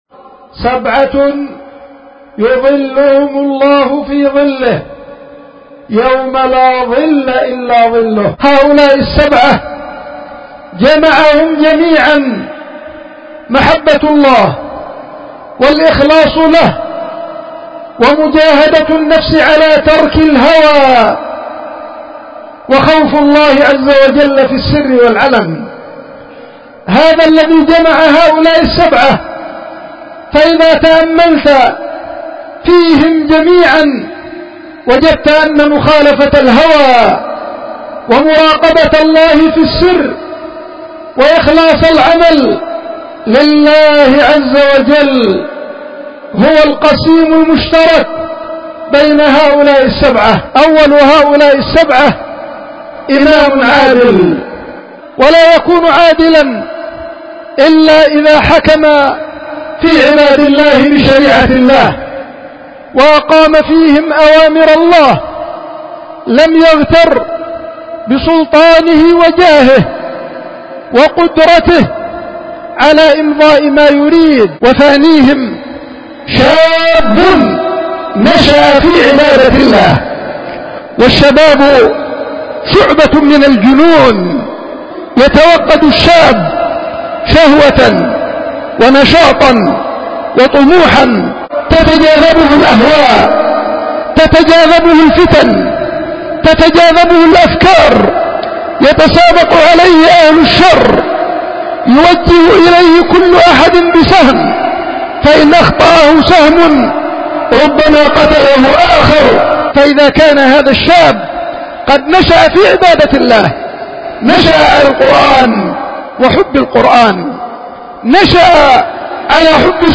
خطبة الجمعة
القيت في دار الحديث في مدينة دار السلام العلمية بيختل المخا